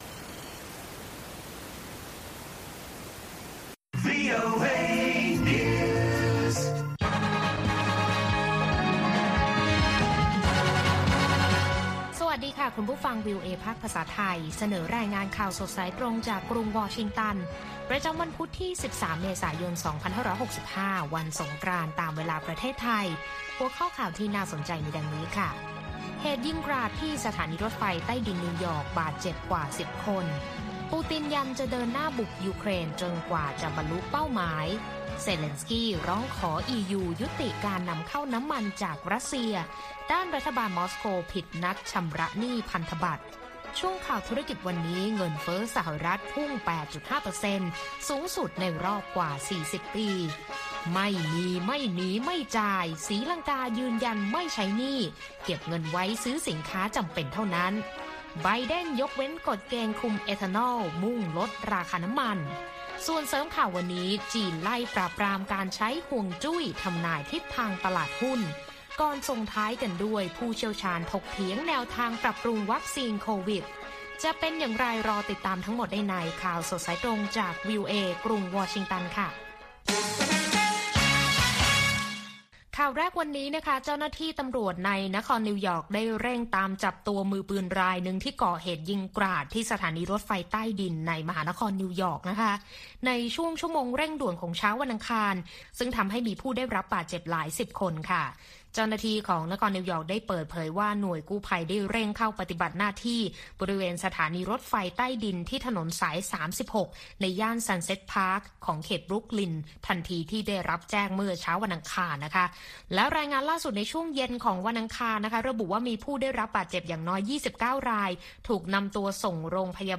ข่าวสดสายตรงจากวีโอเอ ภาคภาษาไทย วันพุธ ที่ 13 เมษายน 2565